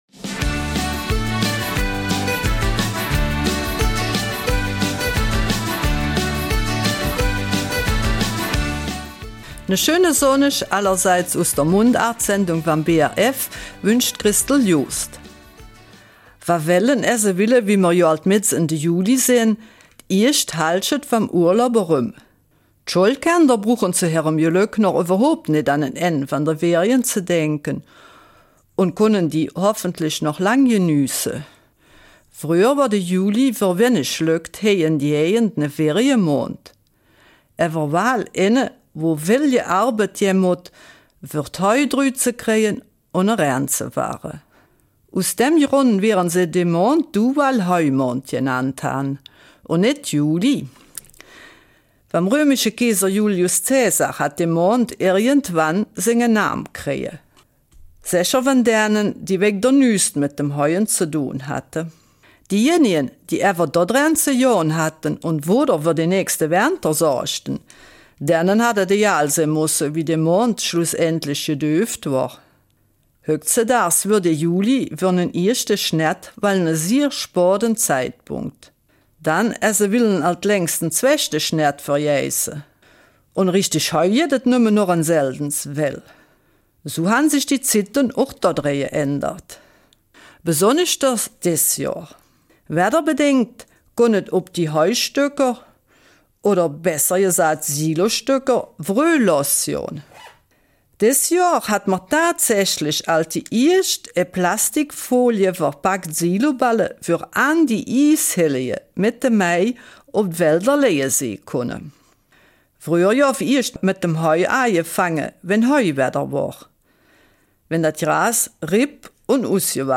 Eifeler Mundart: Juli als Monat der Heuernte